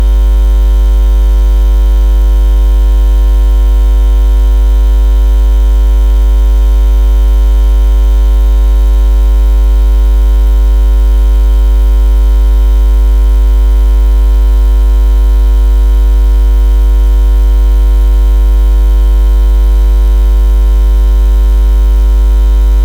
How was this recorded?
Try this recording with less noise: